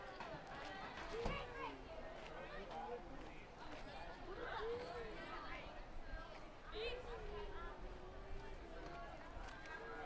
2-people-soud.8e561848ed63943bdf01.mp3